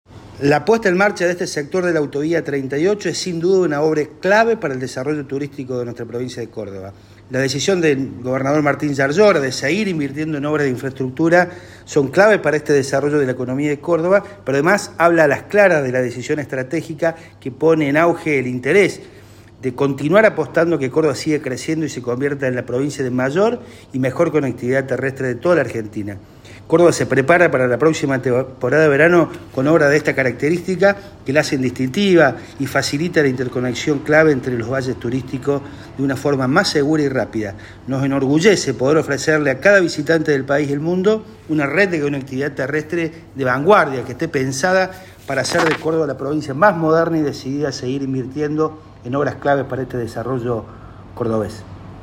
Darío Capitani, presidente de la Agencia Córdoba Turismo
DARIO-CAPITANI-agencia-cordoba-turismo.mp3